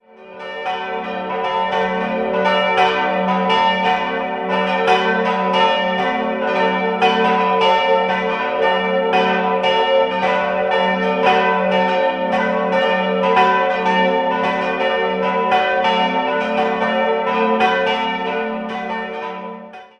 Idealquartett: fis'-a'-h'-d'' Die große Glocke wurde 1885 von der Firma Spannagl in Landshut gegossen, die beiden kleinen sind Werke von Pasolini (Ingolstadt) aus dem Jahr 1768 und Glocke 2 ergänzte Rudolf Perner (Passau) im Jahr 1972.